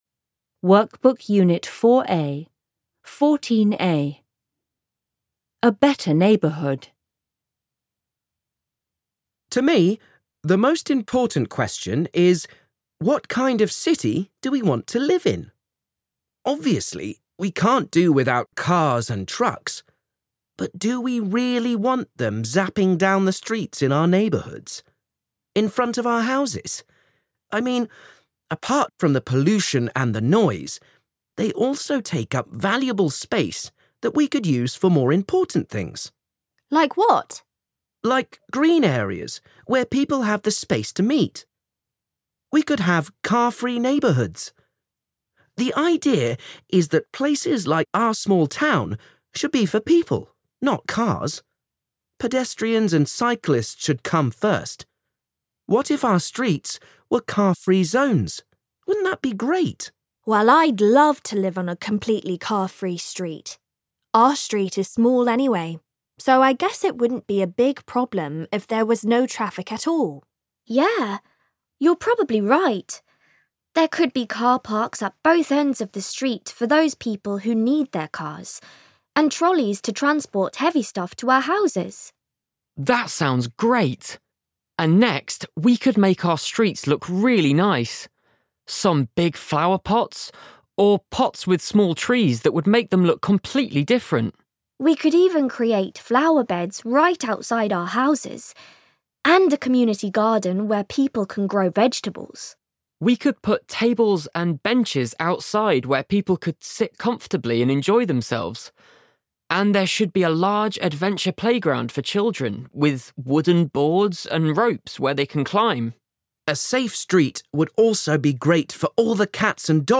unterhalten sich vier Teenager darüber, wie sie sich ihr ideales